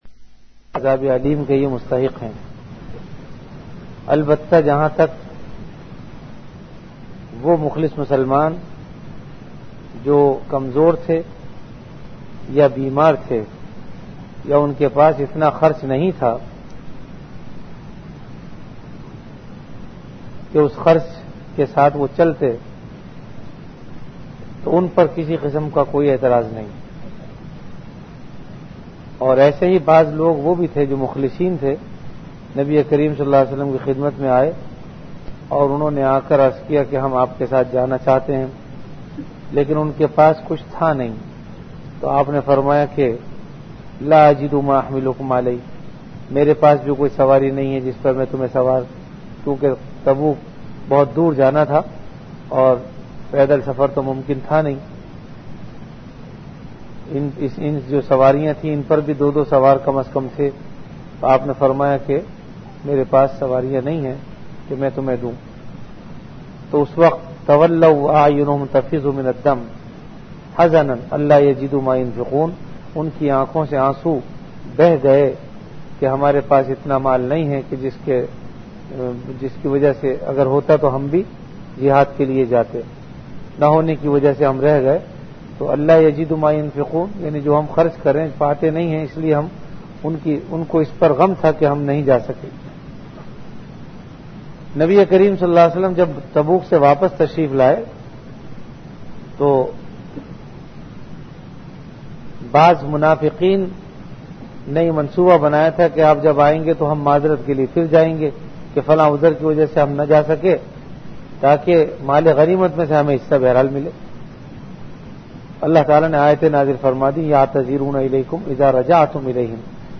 Ramadan - Taraweeh Bayan · Jamia Masjid Bait-ul-Mukkaram, Karachi